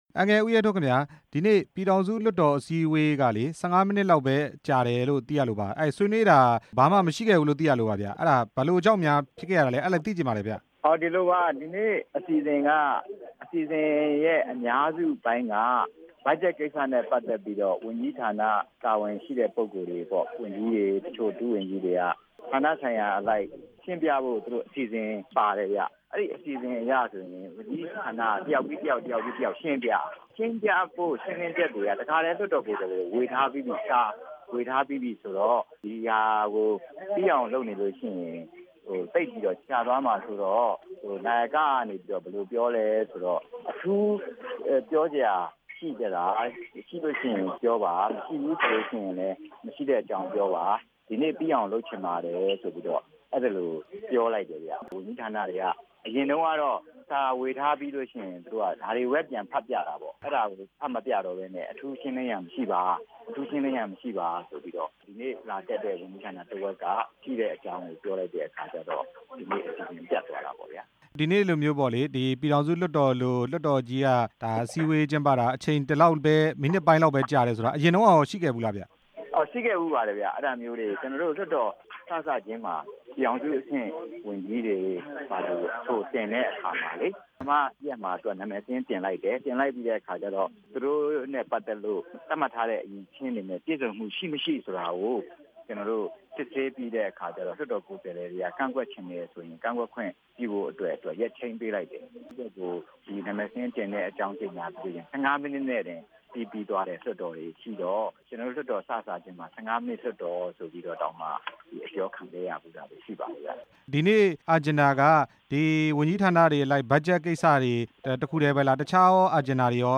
ပြည်ထောင်စုလွှတ်တော်အစည်းအဝေးရဲ့ အခြေအနေ မေးမြန်းချက်